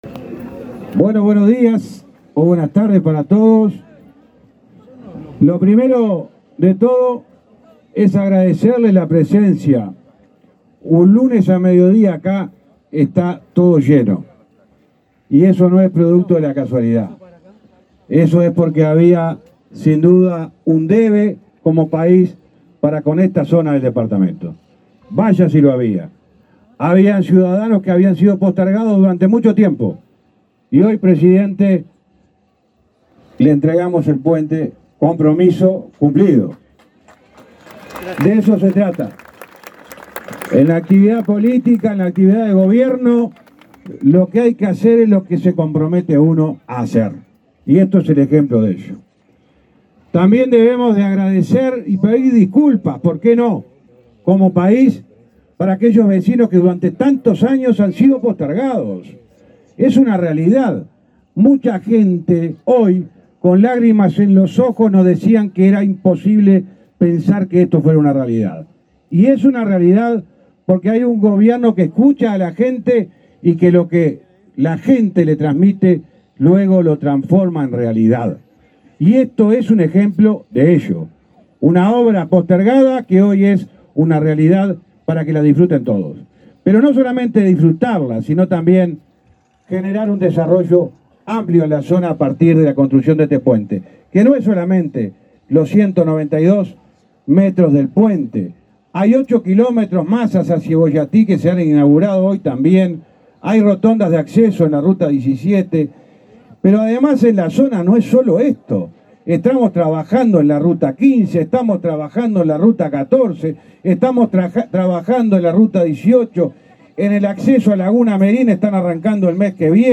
Palabras del ministro de Transporte, José Luis Falero
El ministro de Transporte, José Luis Falero, participó en la inauguración de un puente sobre el río Cebollatí, que unirá las localidades de La